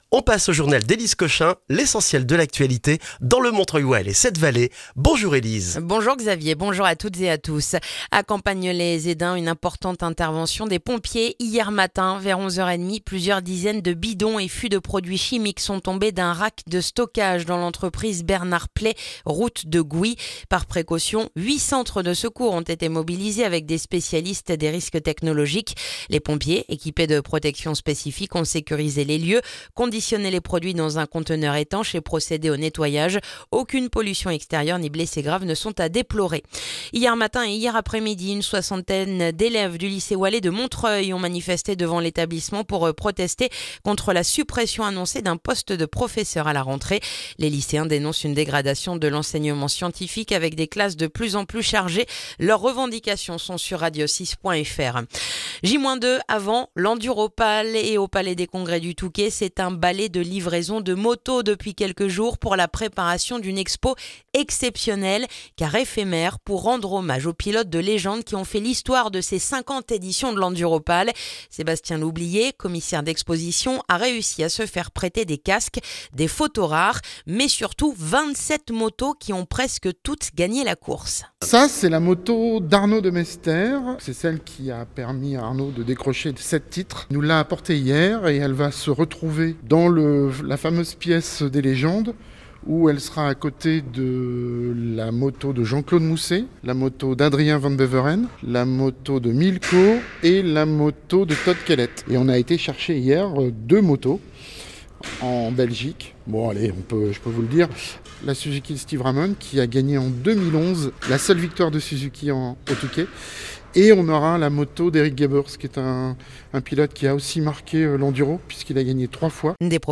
Le journal du mercredi 11 février dans le montreuillois